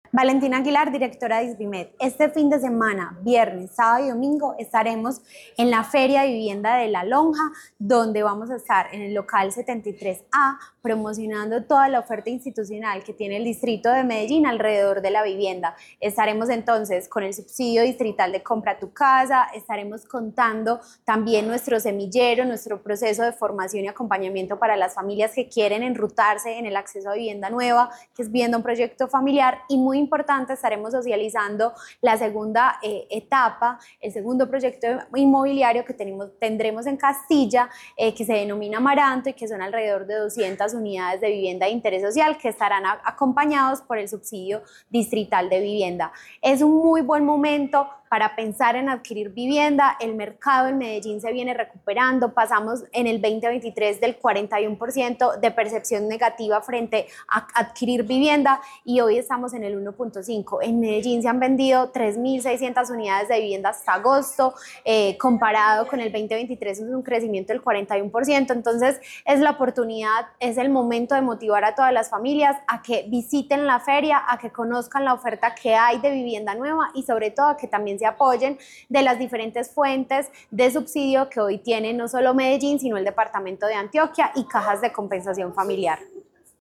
Declaraciones de la directora de Isvimed, Valentina Aguilar Ramírez
Declaraciones-de-la-directora-de-Isvimed-Valentina-Aguilar-Ramirez.mp3